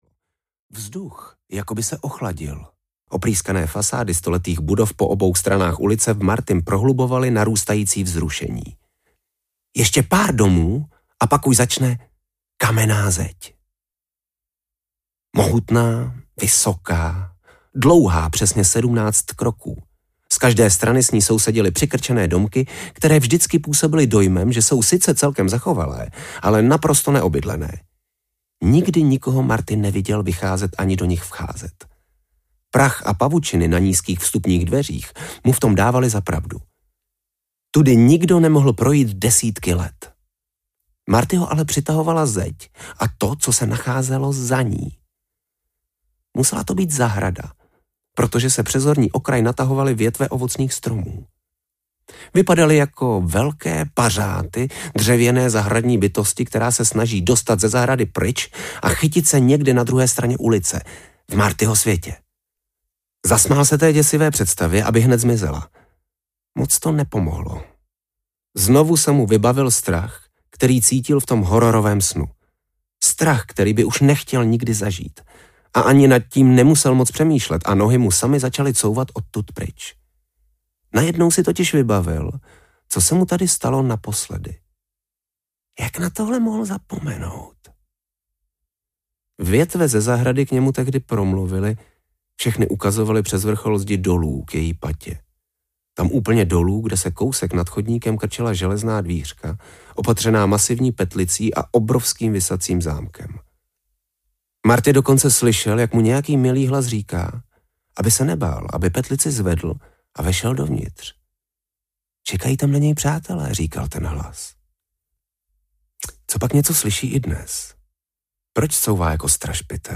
Zahrada a legenda o zachránci audiokniha
Ukázka z knihy